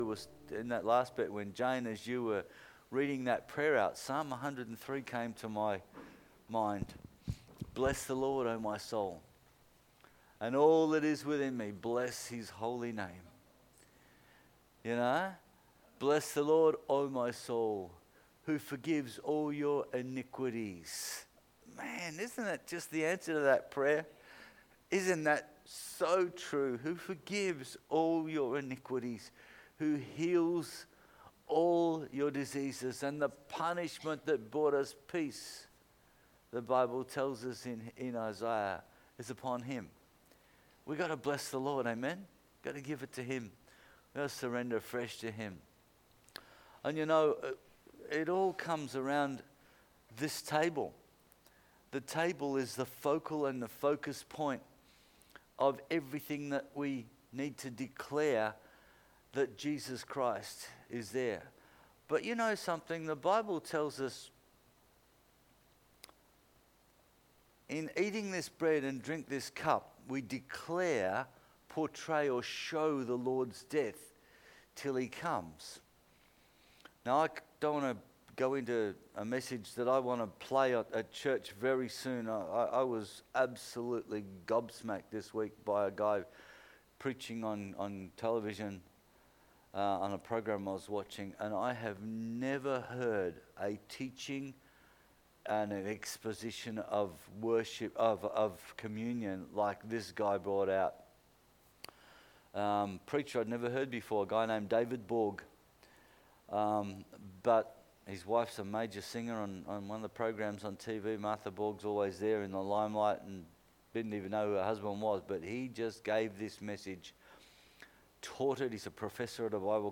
Communion & Sermon – 14/06/20